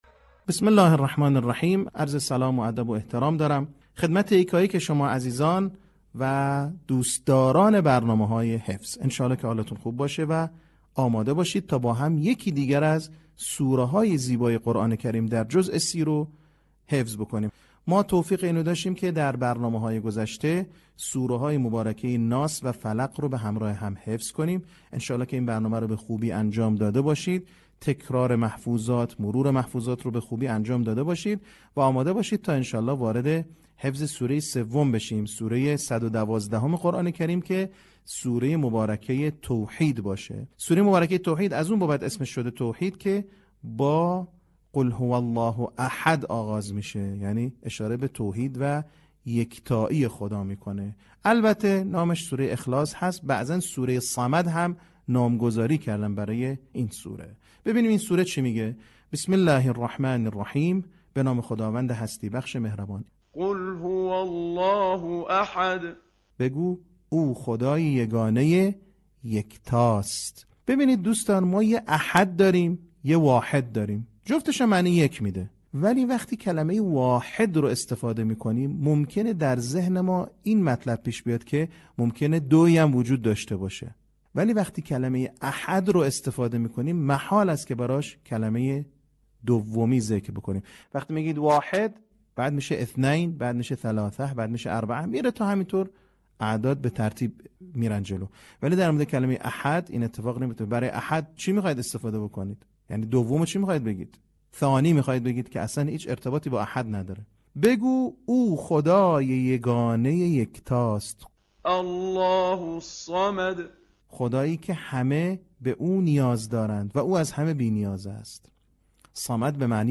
صوت | آموزش حفظ سوره اخلاص